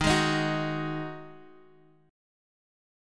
D#mbb5 chord